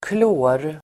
Uttal: [klå:r]